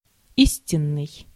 Ääntäminen
France: IPA: [ʁe.ɛl]